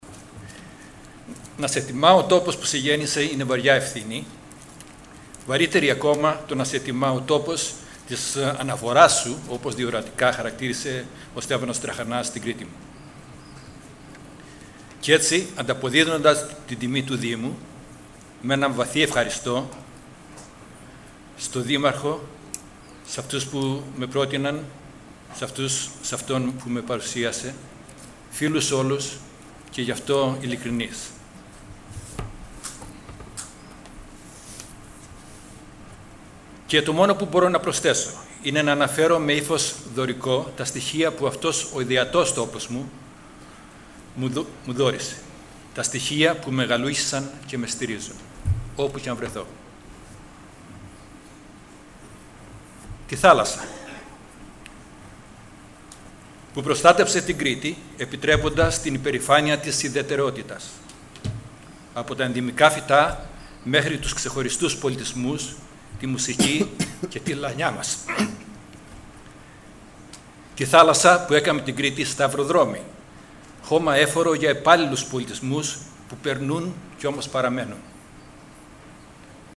Significant personalities of Crete that contributed in the development of Vikelas Municipal Library as well as the development of Art and Literature in Crete, talk about several historical, cultural and literary issues in radio shows, lectures and interviews.
Two lecture are given during the ceremony.